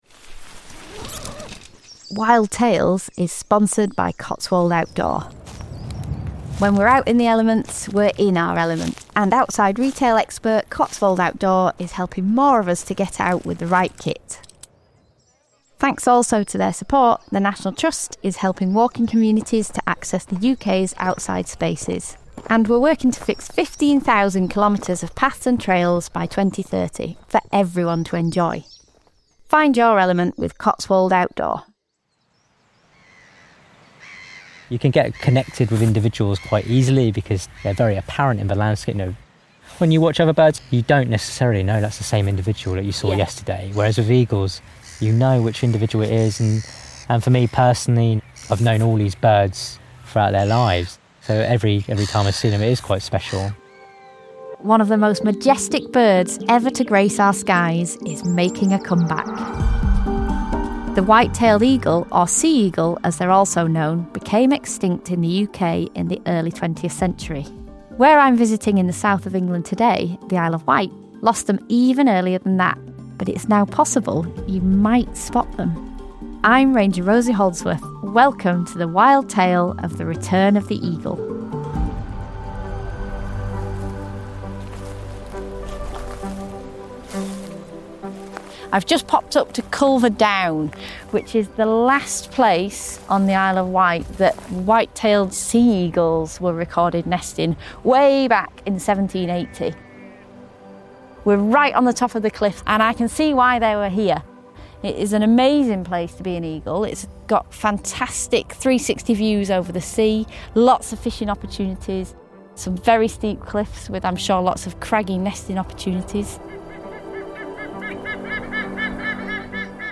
as they try and spot them on the Isle of Wight.